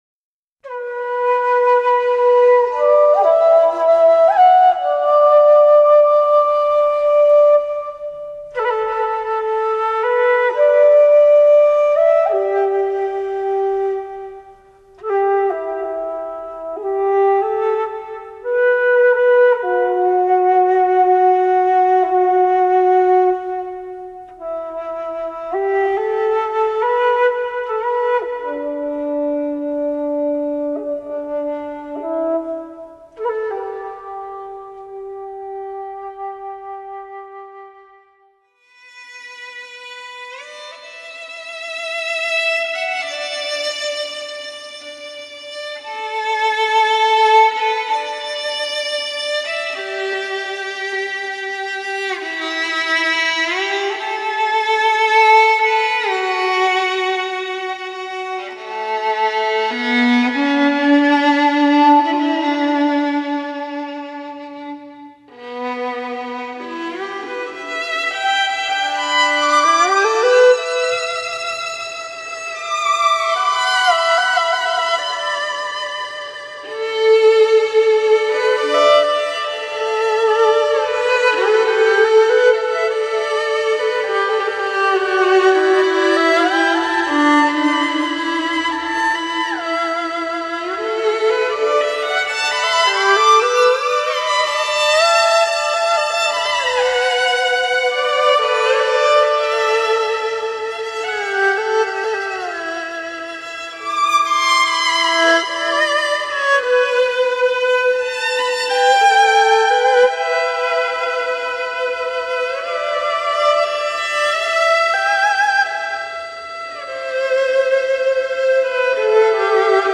乐曲由木音筒箫、火音二胡及小提琴合奏，主理肝血循环和心脏功能，提高体内免疫，调理身心优化脏腑。